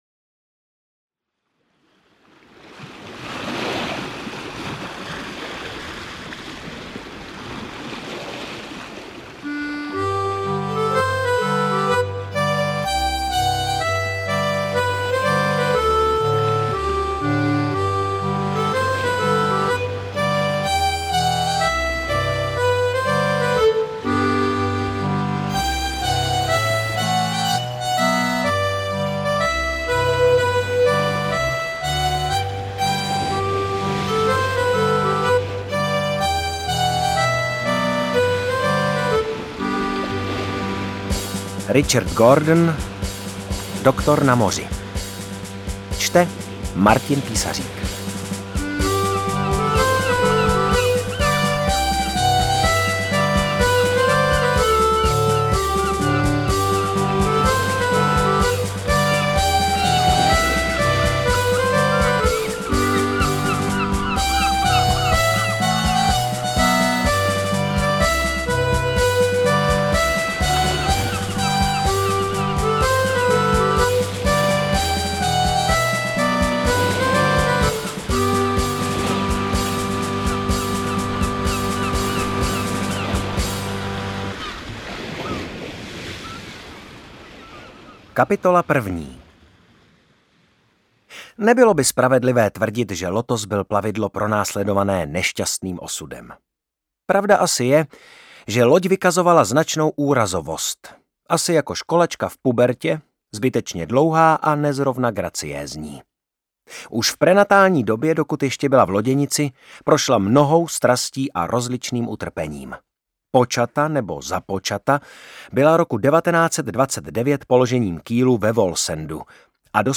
Interpret:  Martin Písařík
AudioKniha ke stažení, 23 x mp3, délka 6 hod. 35 min., velikost 548,7 MB, česky